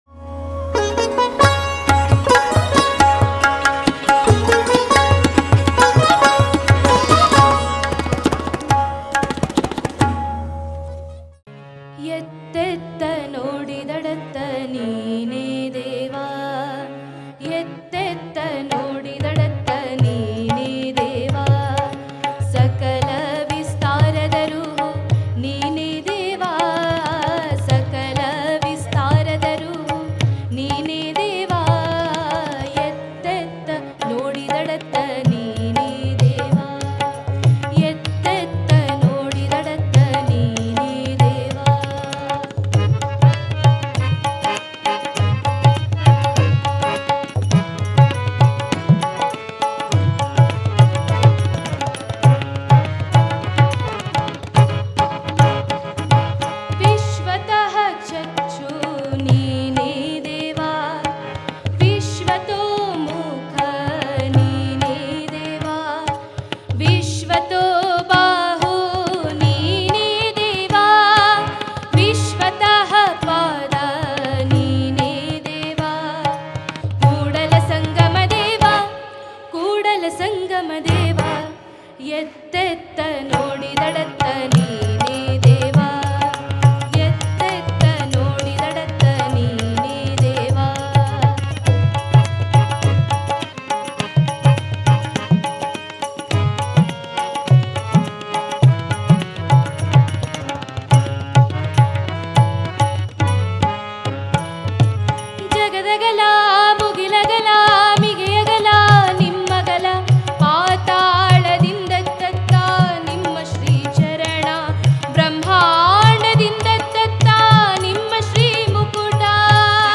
ವಚನ ಗಾಯನ